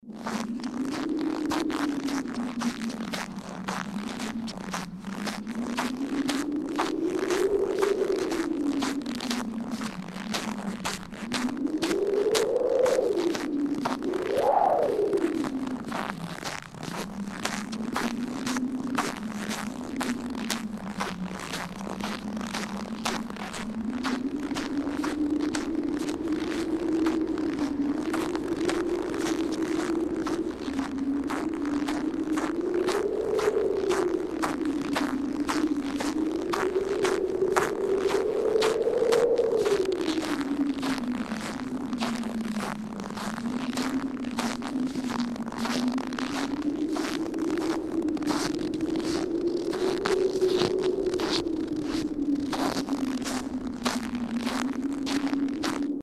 Звук шагов по хрустящему снегу в тридцатиградусную ночь